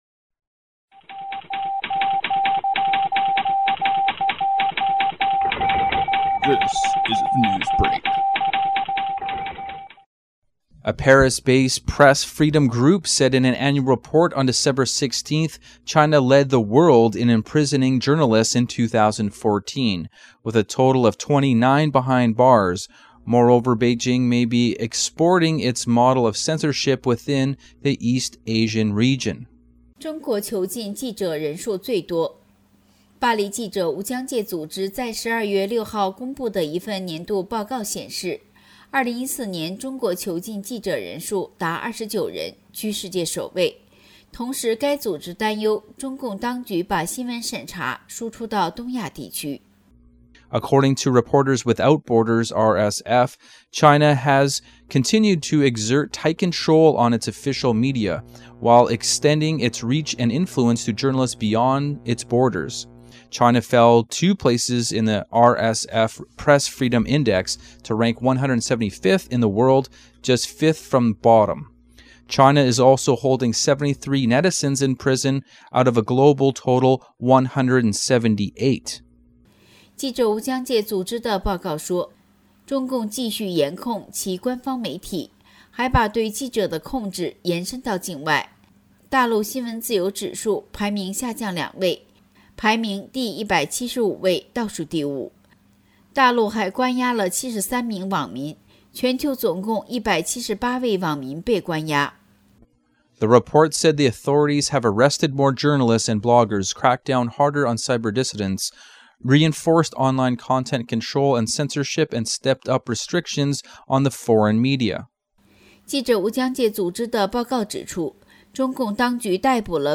Type: News Reports